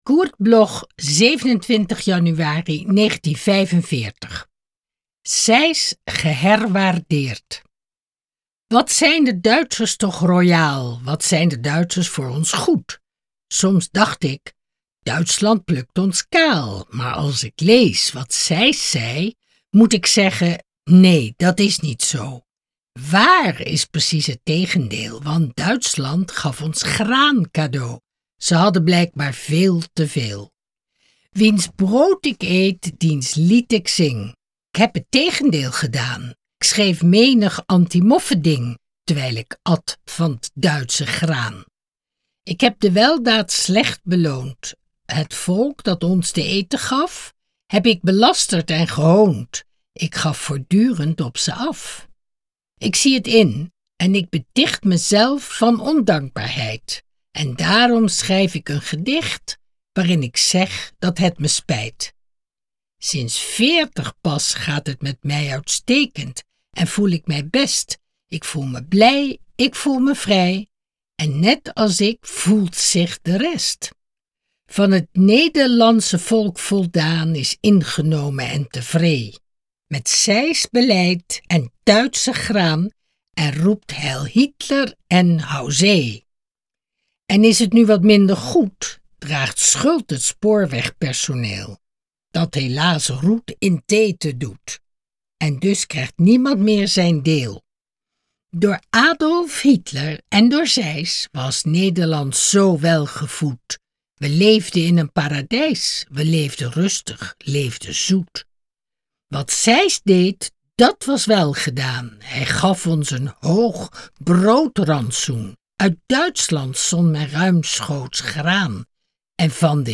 Recording: Studio Levalo, Amsterdam · Editing: Kristen & Schmidt, Wiesbaden